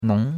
nong2.mp3